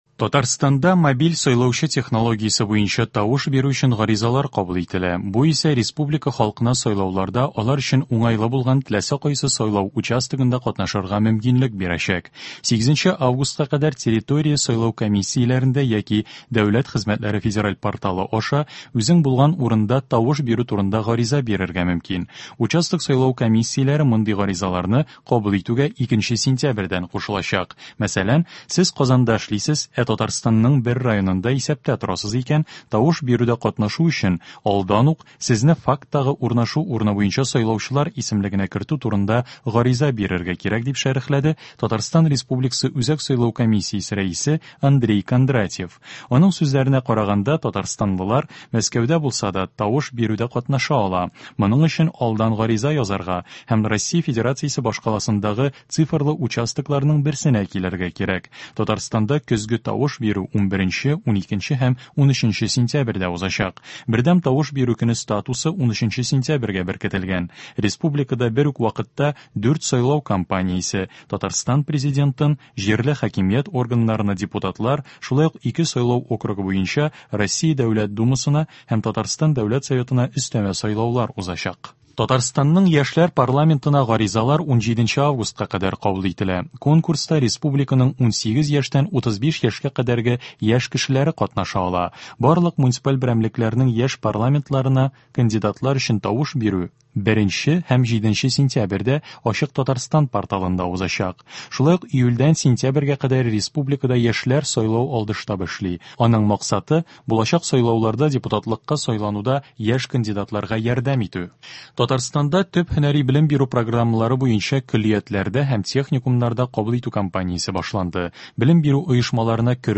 Яңалыклар. 3 август.